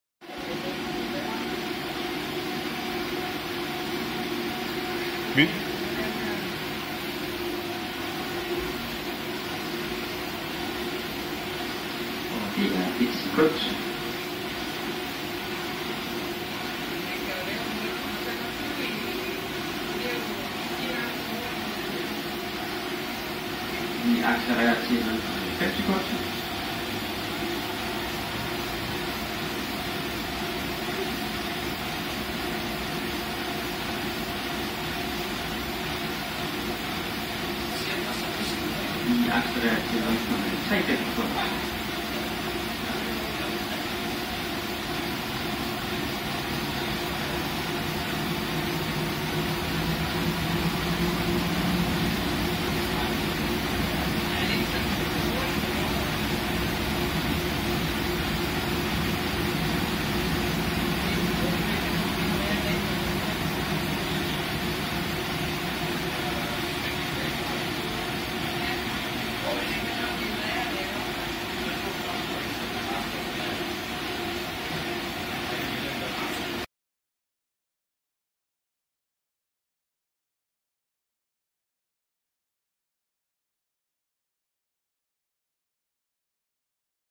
Radiographer Films Inside of a CT scanner spinning at full speed